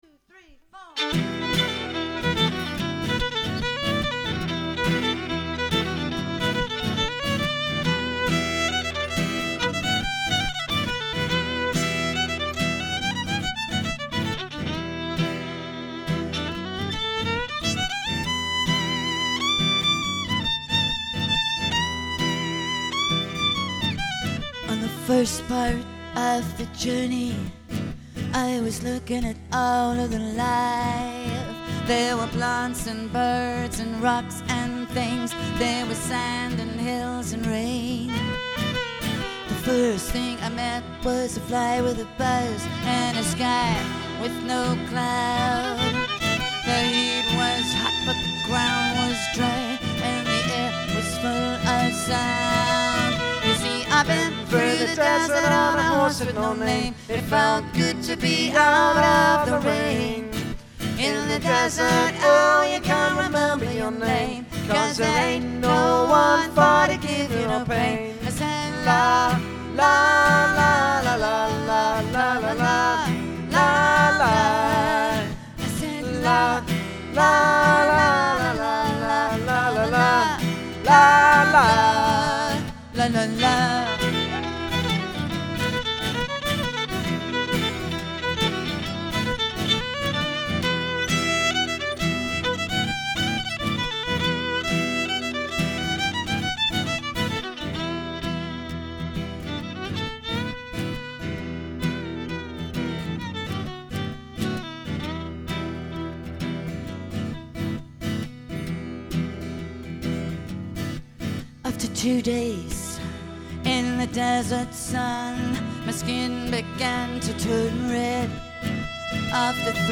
acoustic guitar
electric violin
Audio Clips of the duo recorded live (MP3s):-
+ Irish reel